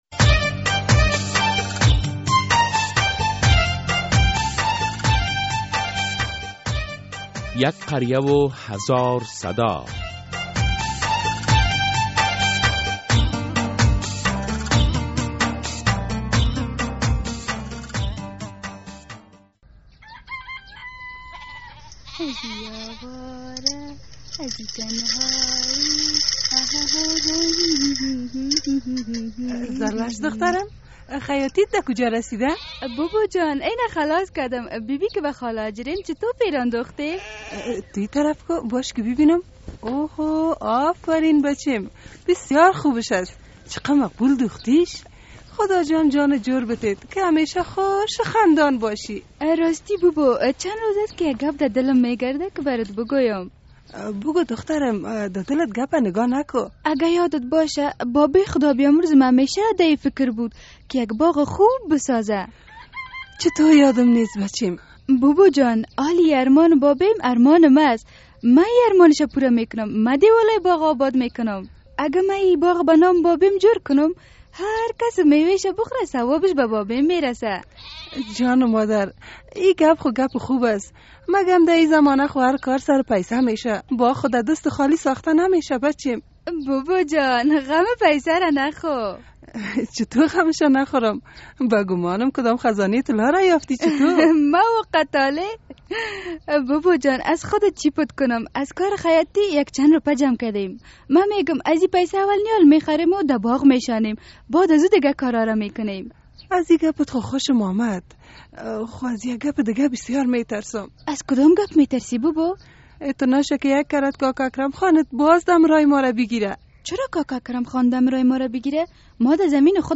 قسمت اول درامهء «یک قریه و هزار صدا»
درامهء «یک قریه و هزار صدا» هر هفته به روز های دوشنبه ساعت 05:30 عصر بعد از نشر فشرده خبر ها از رادیو آزادی پخش می شود.